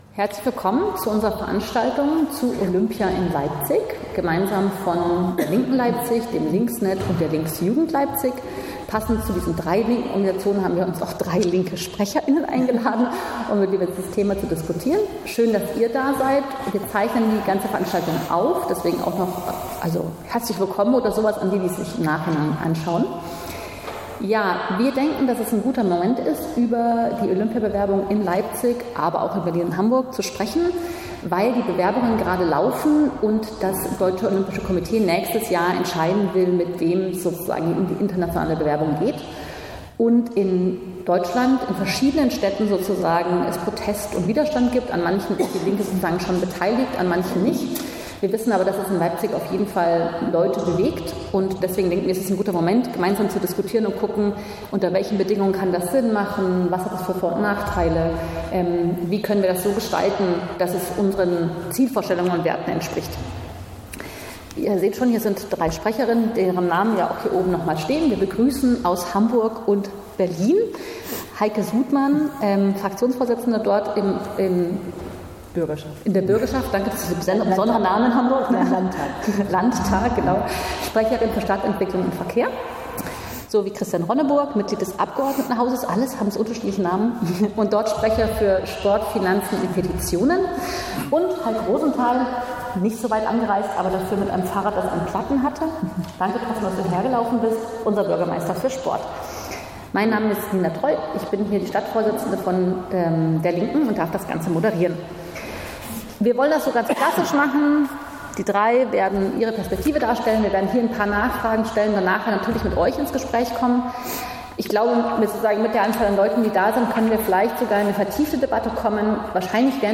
Mitschnitt: Debatte zur Olympiabewerbung von Leipzig mit Berlin